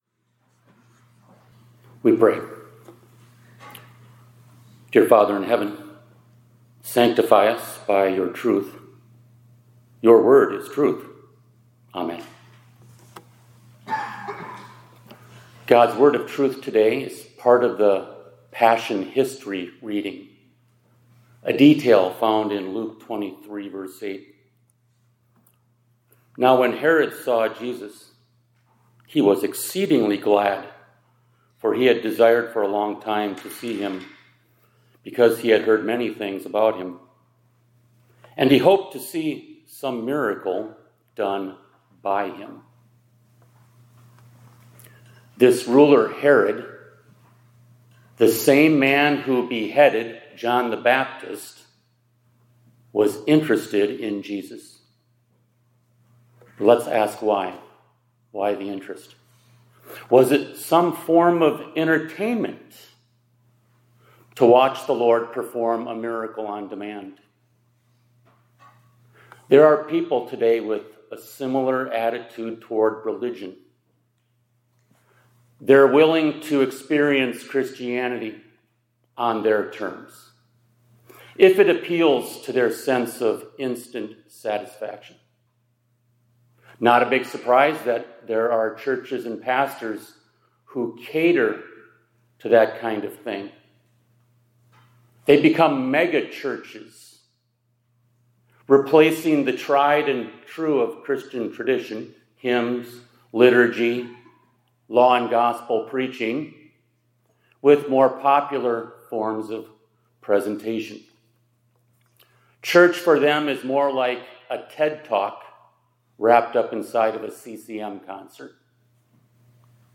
2026-03-04 ILC Chapel — Faith on God’s Terms